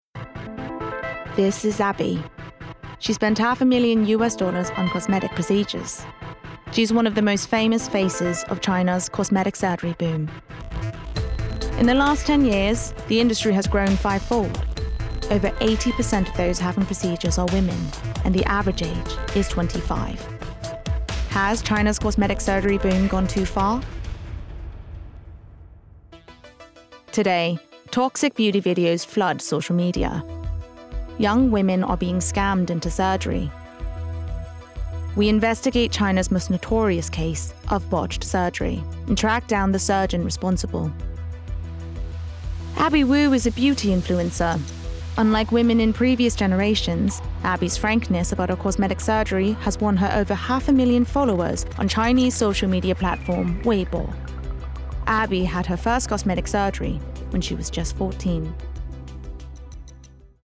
Versatile/Contemporary/Youthful
• Documentary